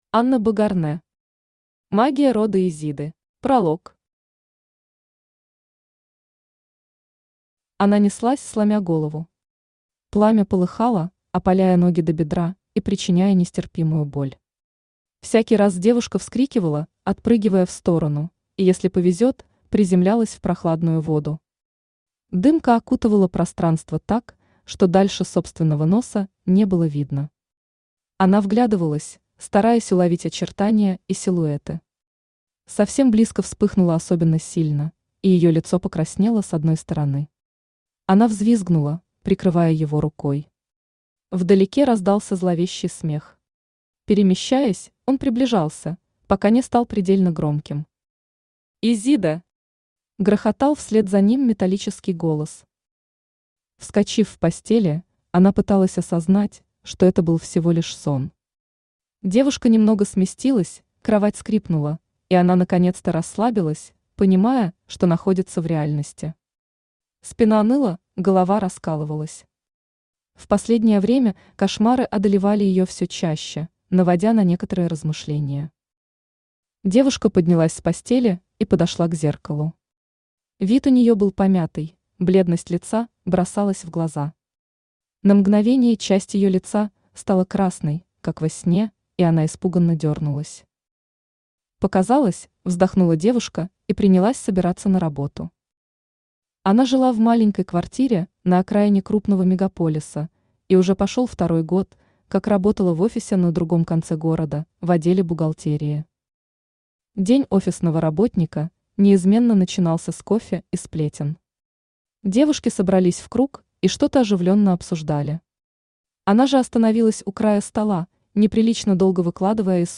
Аудиокнига Магия рода Изиды | Библиотека аудиокниг
Aудиокнига Магия рода Изиды Автор Анна Богарнэ Читает аудиокнигу Авточтец ЛитРес.